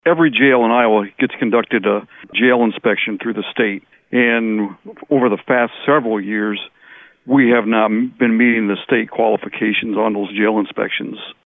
Osceola County Sheriff Kevin Wollmuth tells us the main reason the improvements are needed.